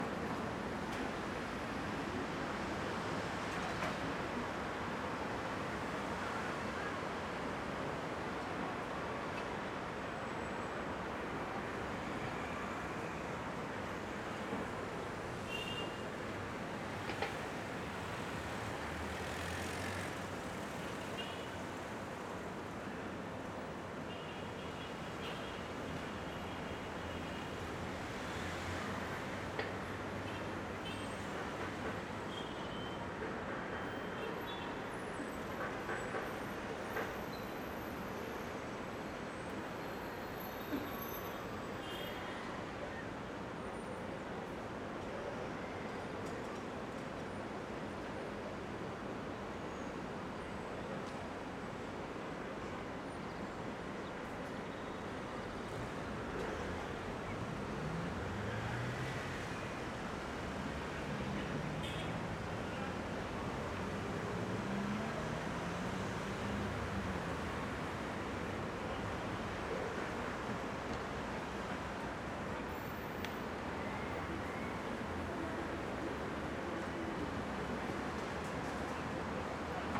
Sci-Fi Sounds / Hum and Ambience / City Loop 2.wav
City Loop 2.wav